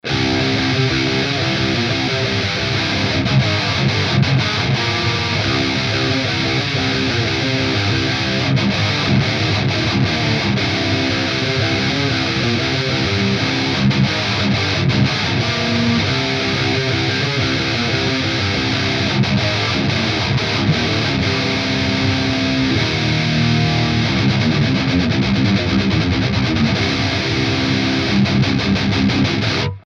Wenn ich den Amp beschreiben müsste würde ich sagen er hat die Brutalität und ist charakterlich verwand mit nem 5150, dabei aber irgendwie "saftiger" und "wärmer".
hier ist mal ein kleiner Clip...immer gleich Regler-Einstellung,m nur unterschiedliche Mics:
beide Mics
natürlich NULL Nachbearbeitung, also auch kein HP/LP oder irgendwatt.